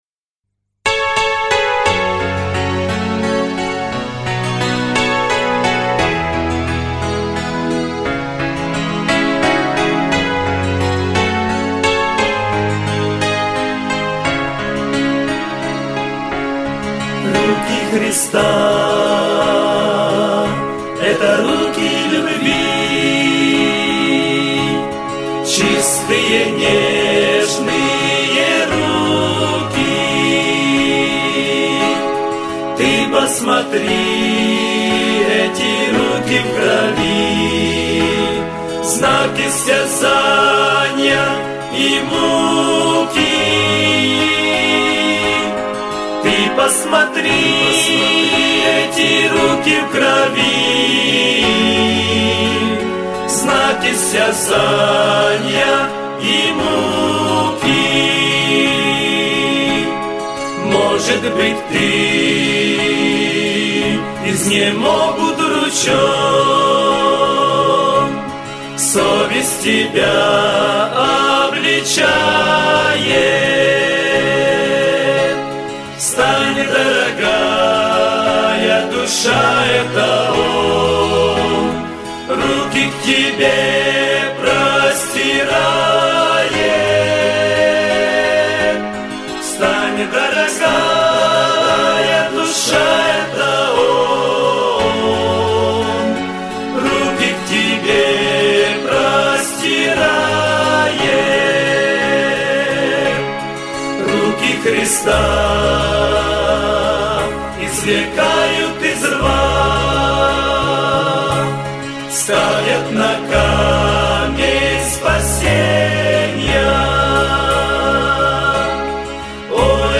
520 просмотров 797 прослушиваний 110 скачиваний BPM: 81